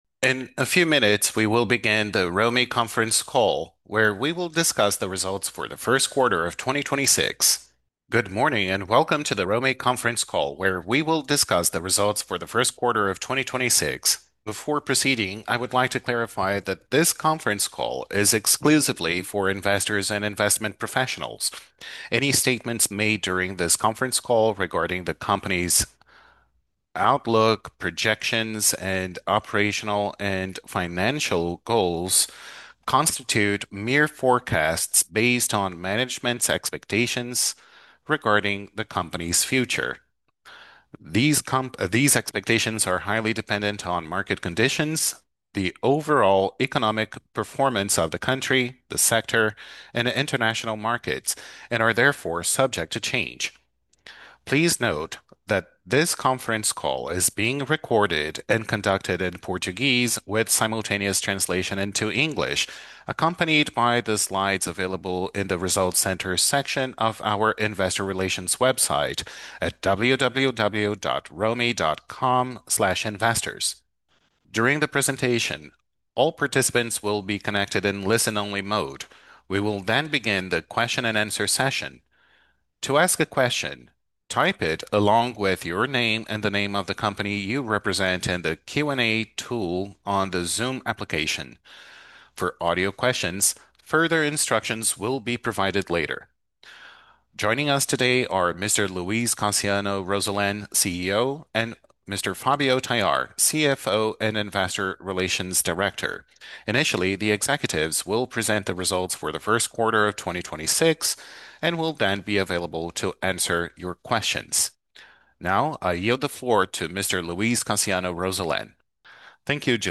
Audio from Quarter Teleconference